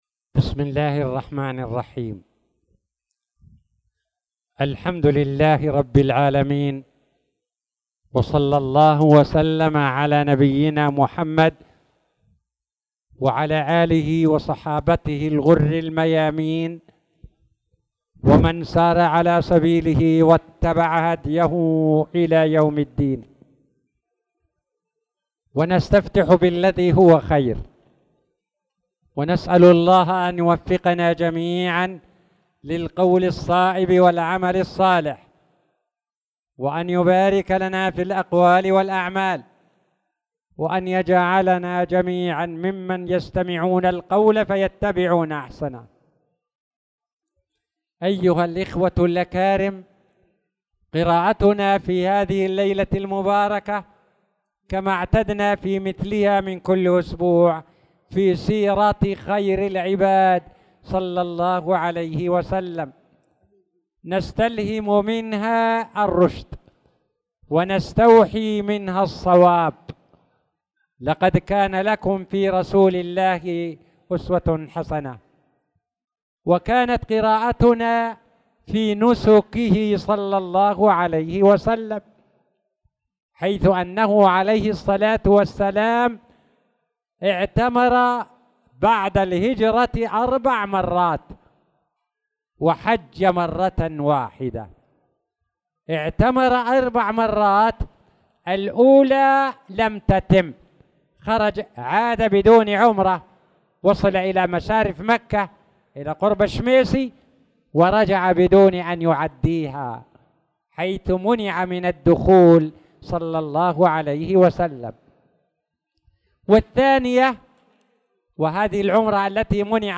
تاريخ النشر ٥ شعبان ١٤٣٧ هـ المكان: المسجد الحرام الشيخ